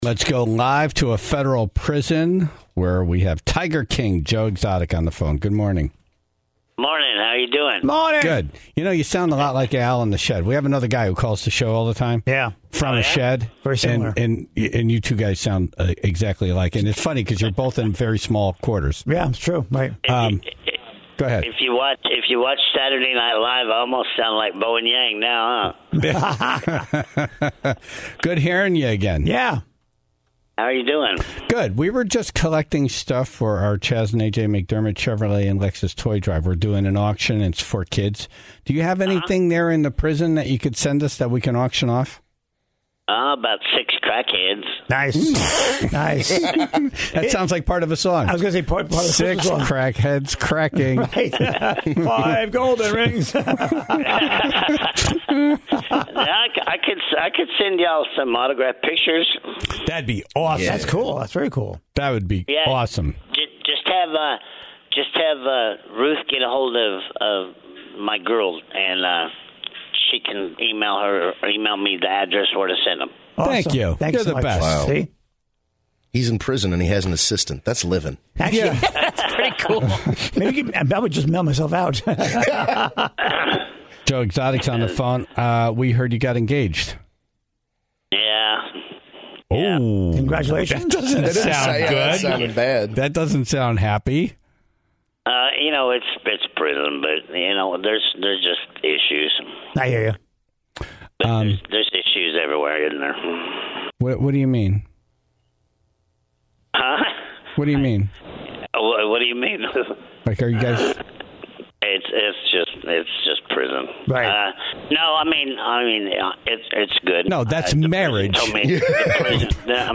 took a call from Federal Prison this morning